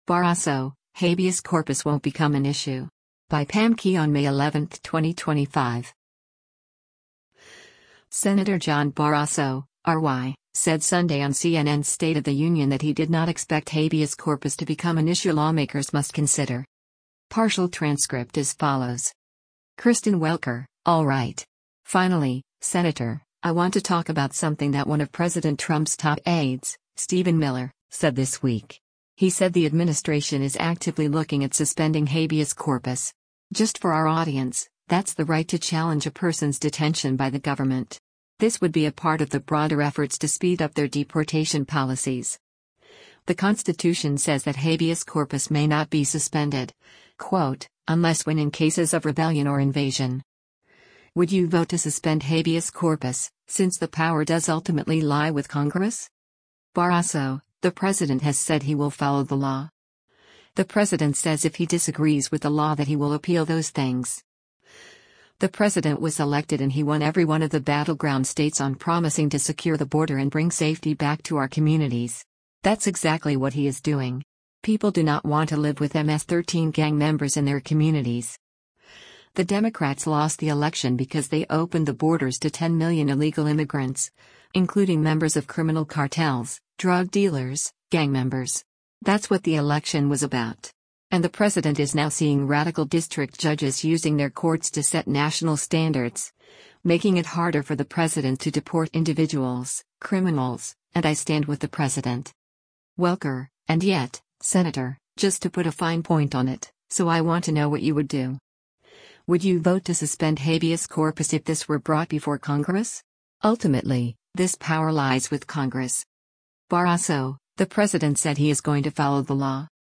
Senator John Barrasso (R-WY) said Sunday on CNN’s “State of the Union” that he did not expect habeas corpus to become an issue lawmakers must consider.